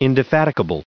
added pronounciation and merriam webster audio
424_indefatigable.ogg